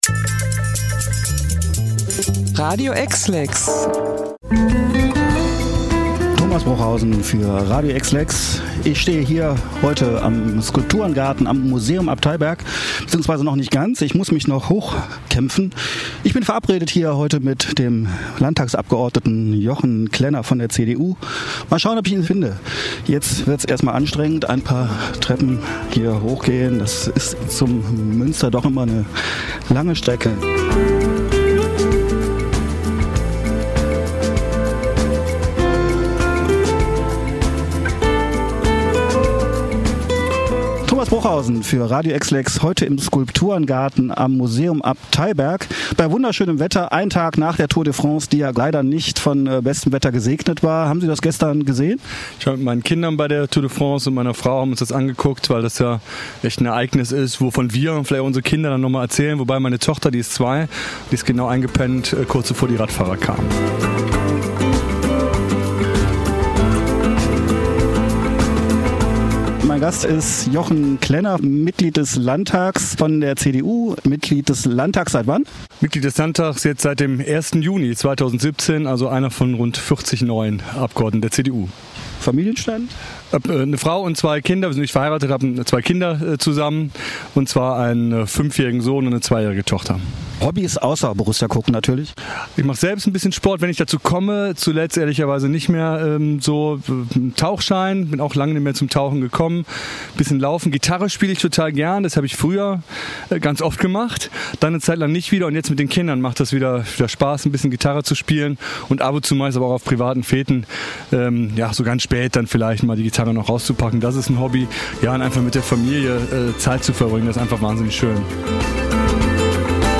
Radio-EXLEX-Interview-Jochen-Klenner.mp3